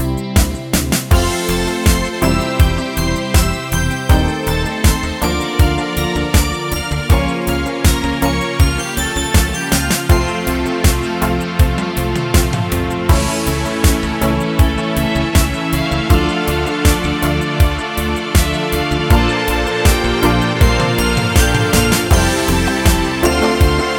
no Backing Vocals Duets 4:10 Buy £1.50